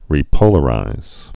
(rē-pōlə-rīz)